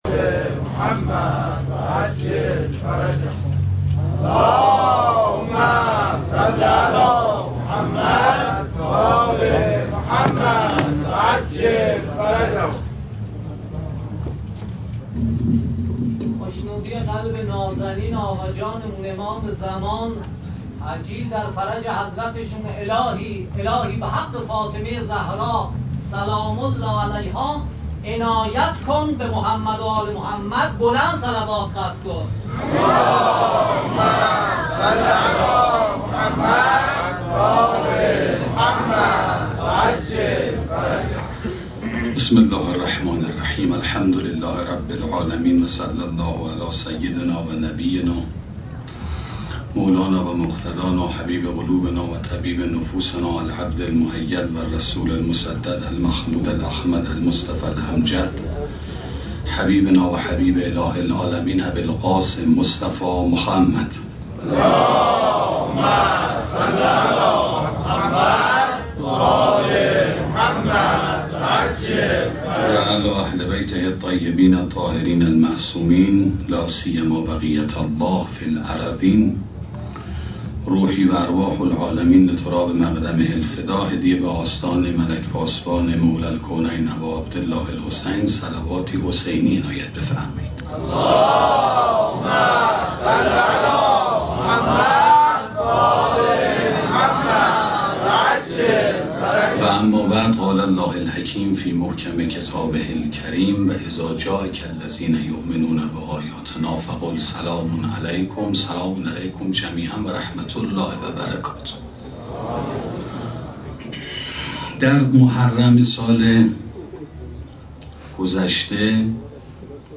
سخنرانی زیارت اهل بیت